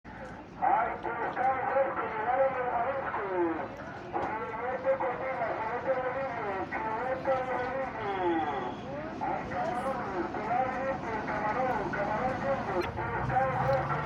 Vendedor de Pescado CARTAGO